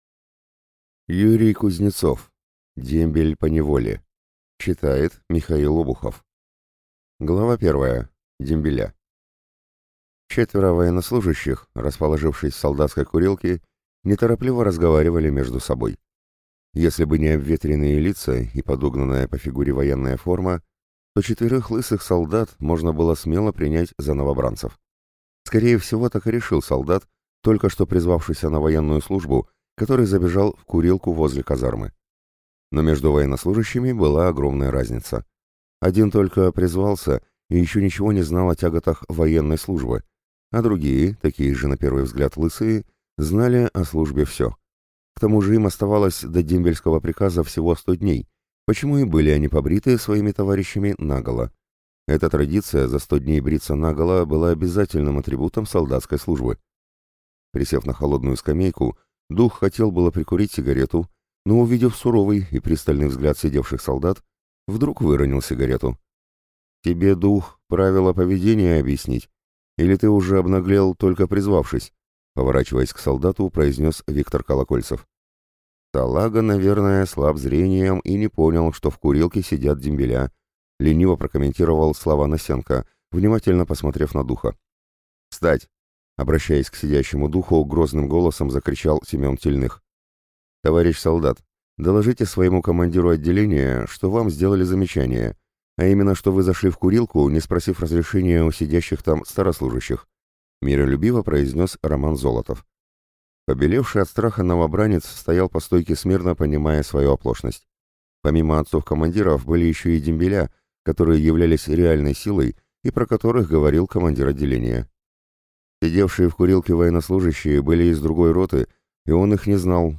Аудиокнига Дембель поневоле | Библиотека аудиокниг